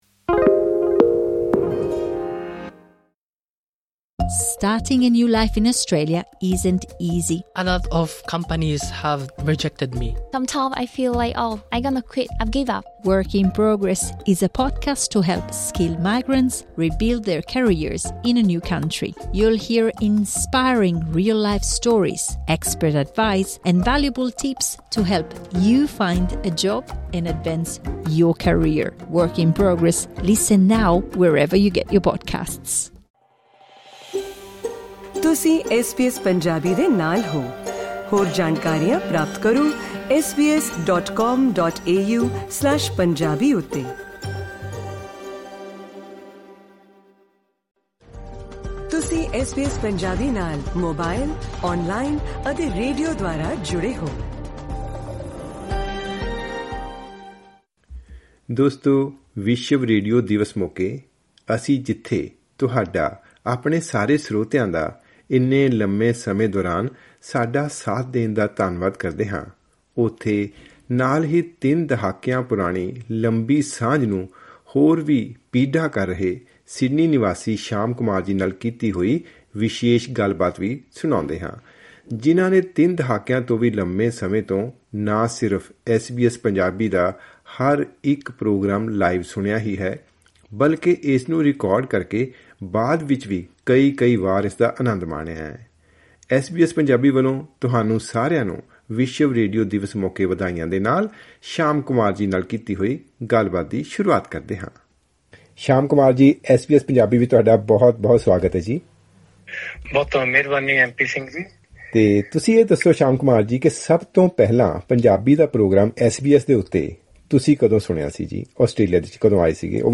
ਗੱਲਬਾਤ